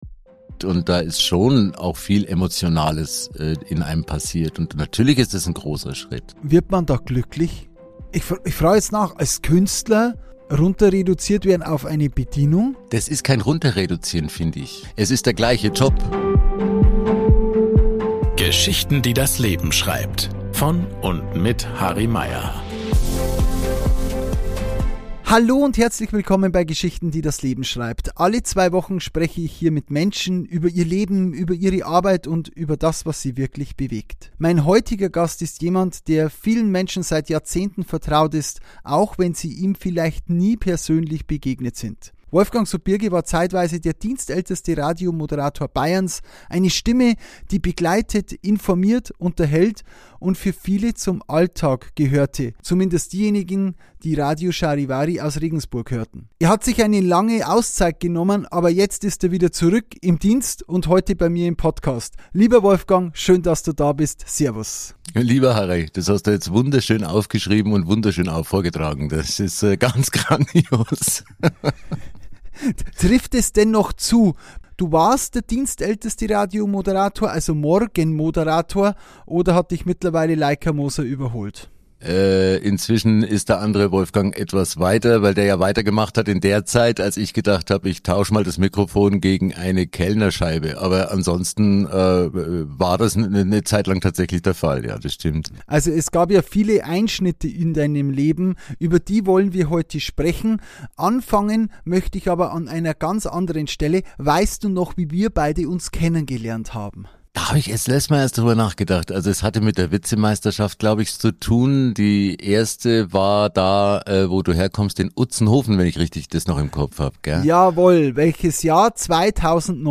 Ein ehrliches, nahbares Gespräch über Identität, Liebe, Loslassen – und darüber, dass ein Neuanfang manchmal kein Ende, sondern ein zweites Kapitel ist.